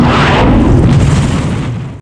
rocket_ll_shoot_crit.wav